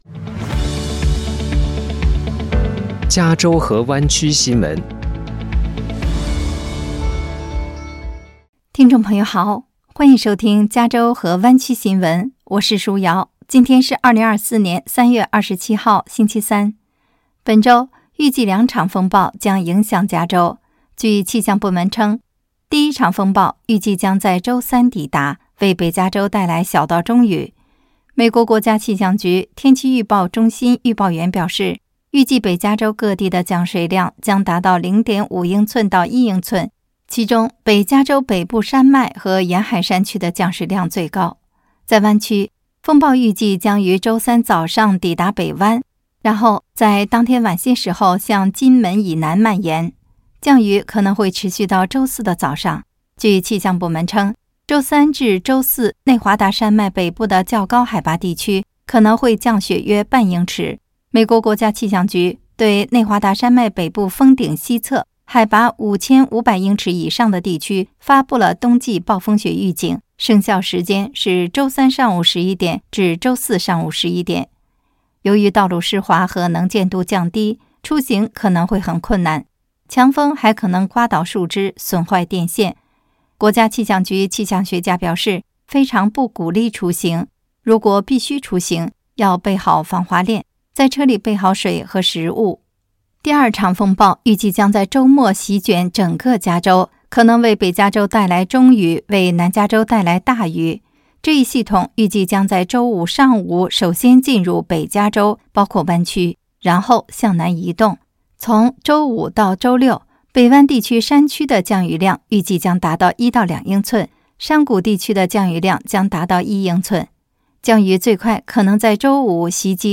配音